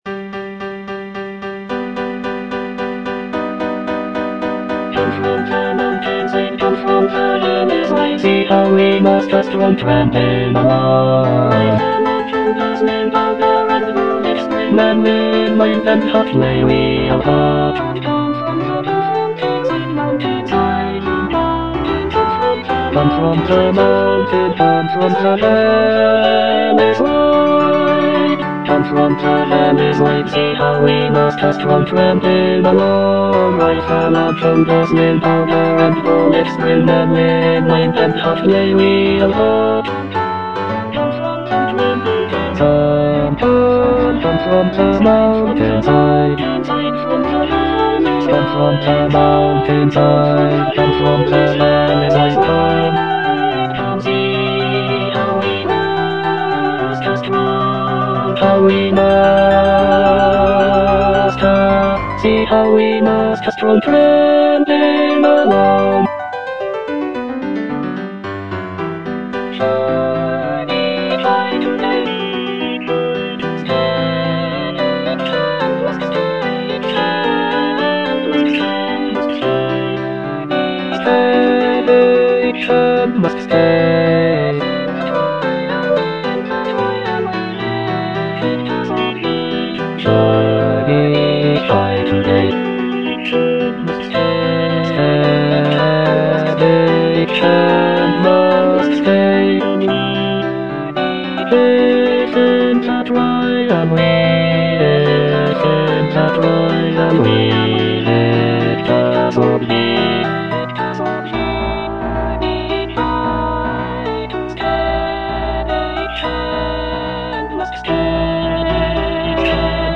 (bass II) (Emphasised voice and other voices)